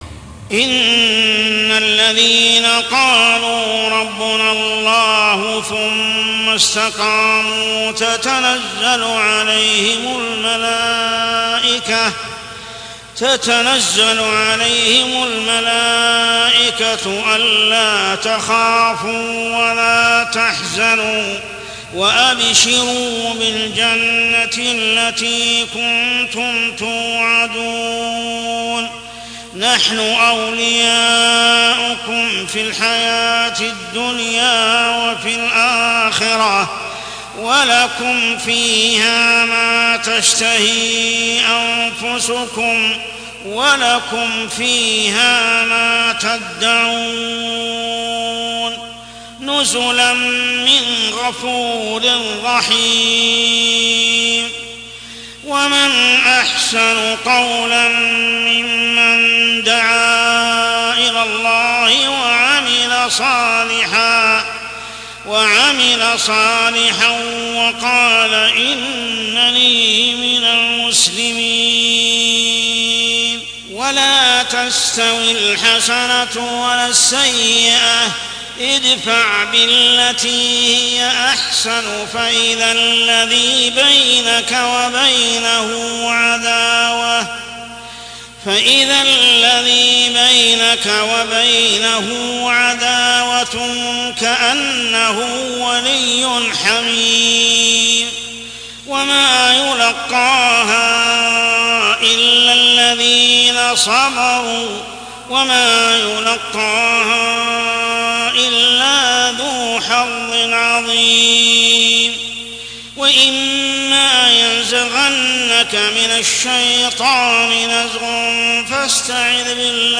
عشائيات شهر رمضان 1424هـ سورة فصلت 30-36 | Isha prayer Surah Fussilat > 1424 🕋 > الفروض - تلاوات الحرمين